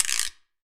9LONGGUIR.wav